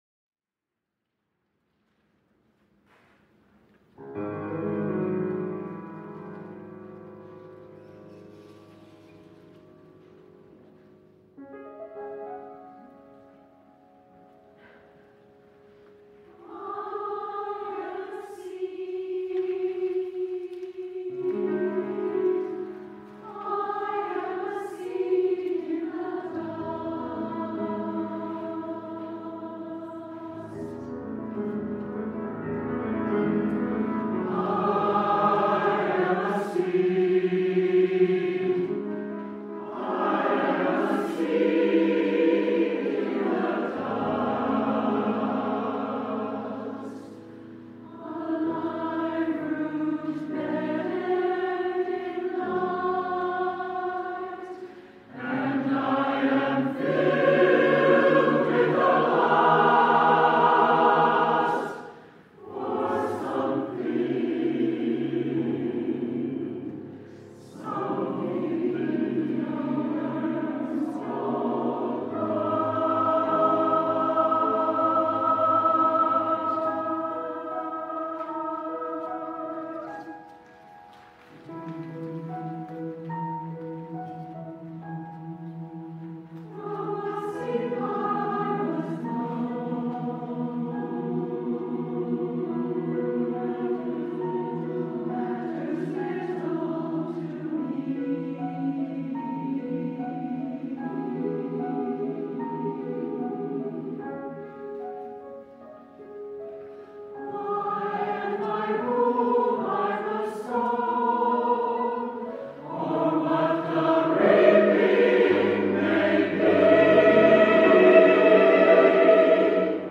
SATB, piano 4:45 $2.75